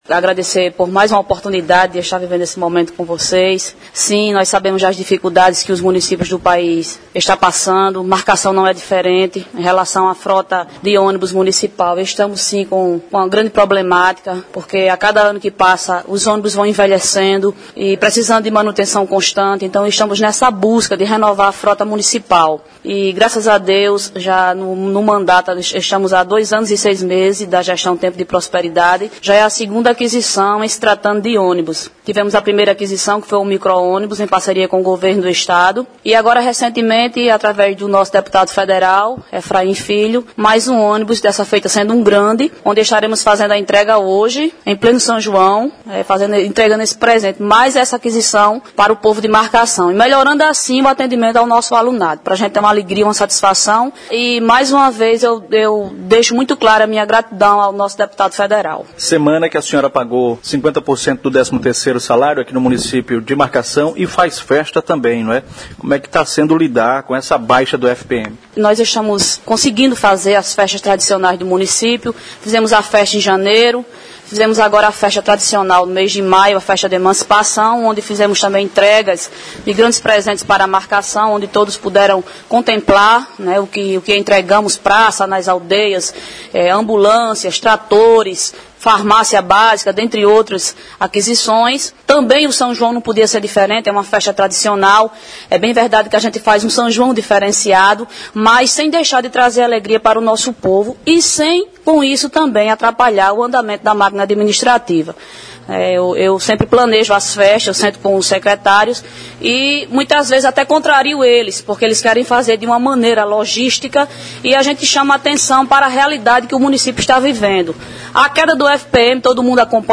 Entrevista abaixo: